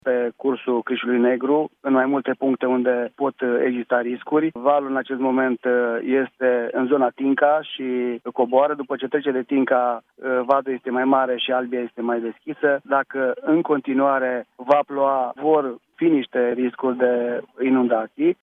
20mai-08-tiplea-prefect-bihor-despre-pericole-inundatii.mp3